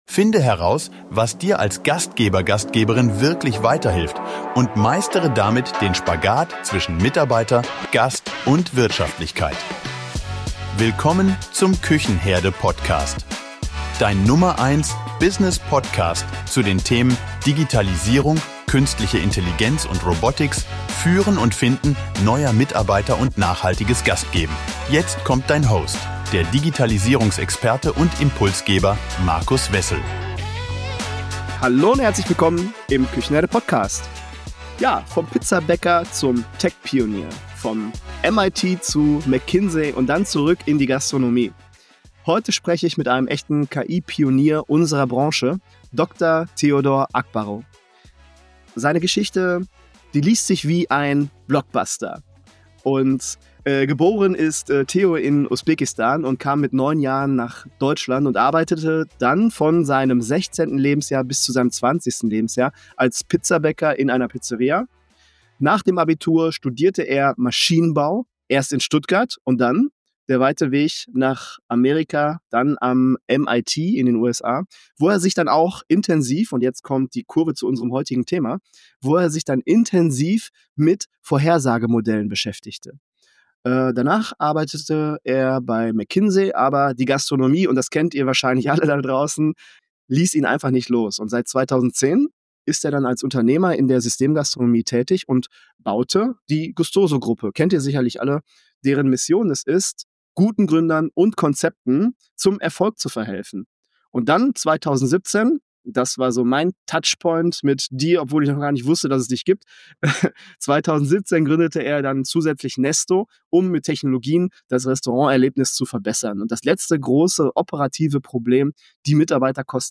Im Gespräch wird klar – KI ist kein Zukunftsthema mehr, sondern Gegenwart.